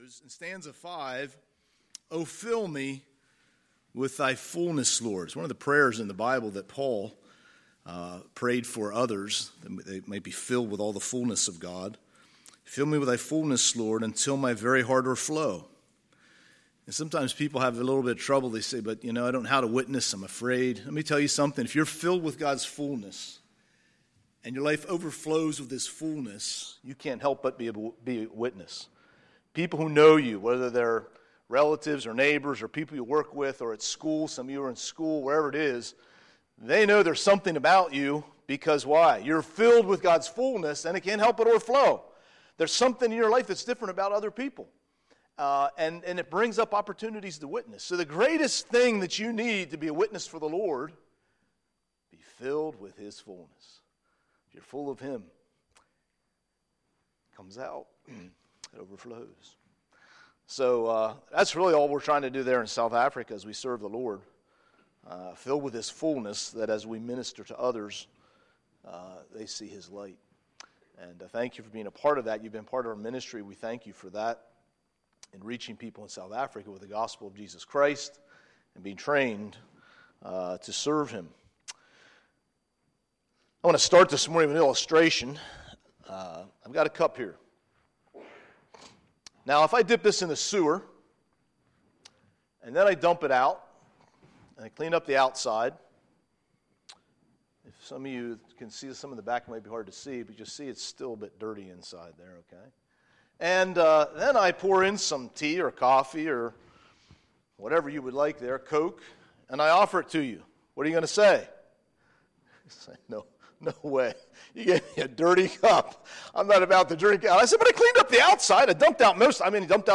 Sunday, September 22, 2019 – Missions Conference Session 2